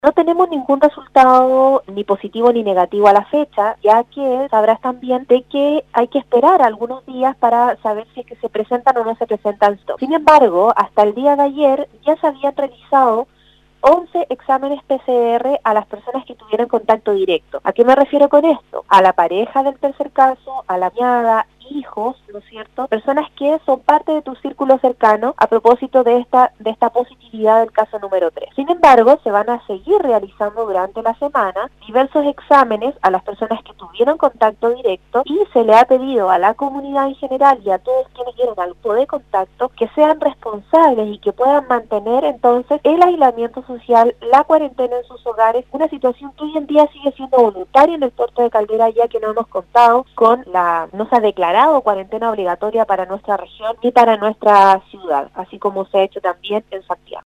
sostuvo un contacto telefónico con Nostálgica donde se refirió al tercer caso positivo confirmado de Covid-19 en la comuna